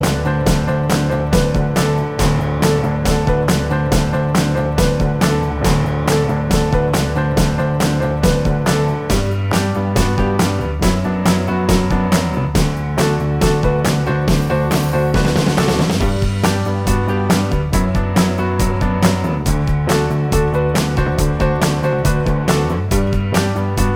Minus Lead Guitar Rock 3:52 Buy £1.50